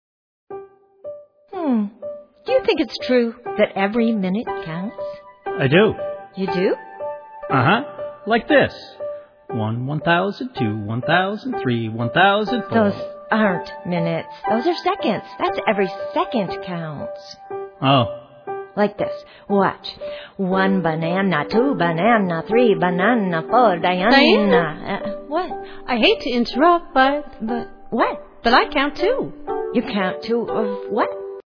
lively story-songs